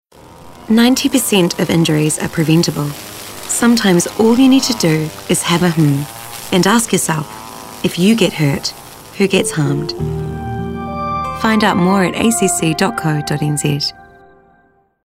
ACC-Nurturer-Radio15s-90-percent.mp3